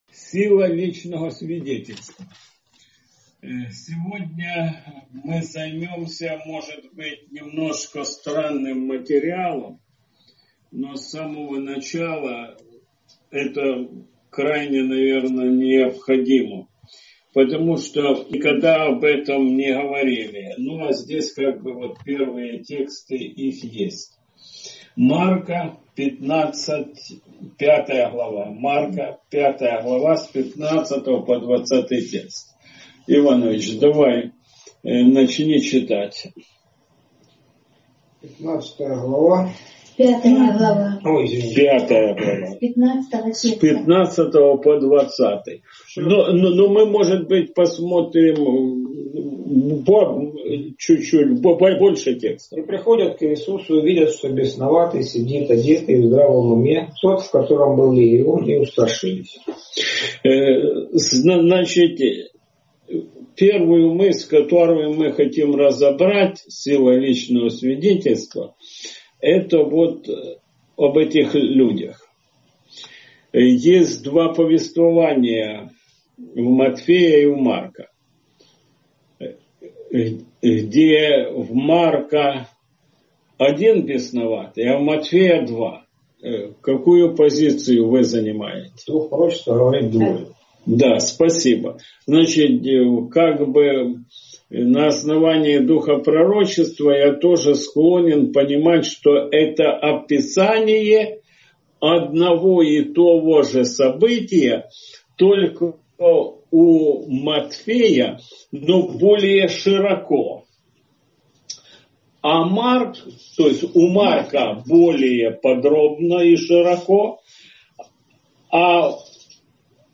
СШ № 2 8 июля 2020 | Категория: Субботняя Школа.